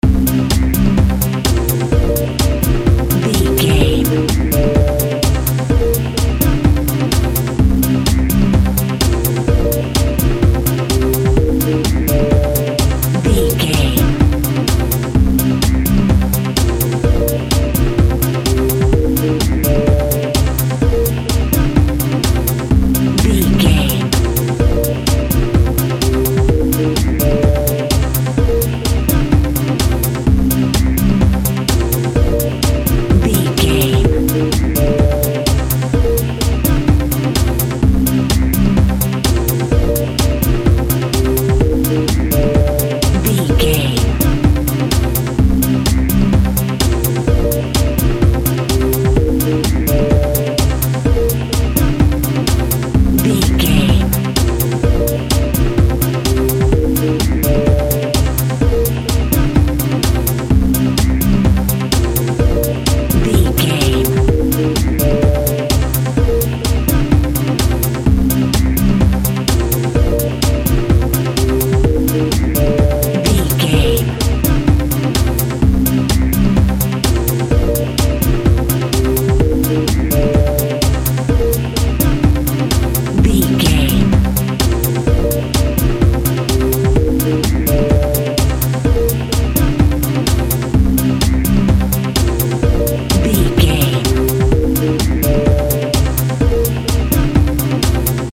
Space Electronics.
Fast paced
Mixolydian
B♭
dark
futuristic
driving
energetic
synthesiser
drum machine
Drum and bass
break beat
sub bass
synth lead